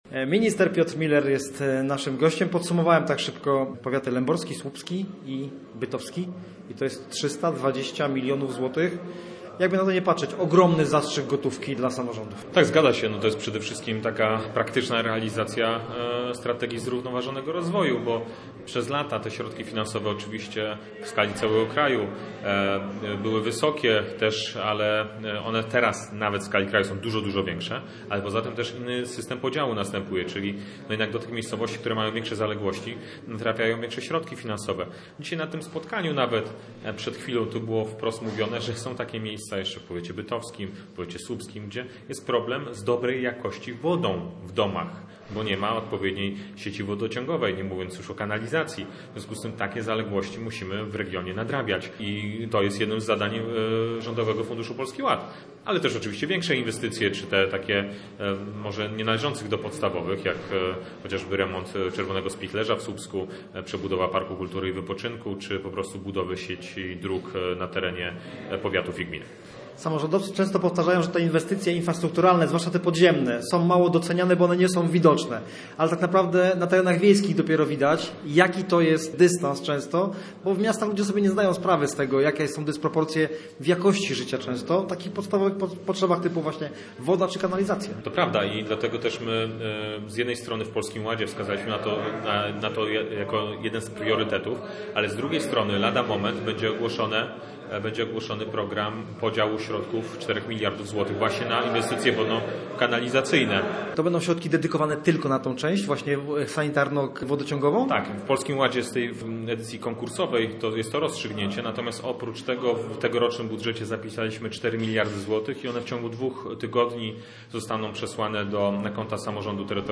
Rzecznik rządu był gościem miejskiego programu Radia Gdańsk Studio Słupsk 102 FM.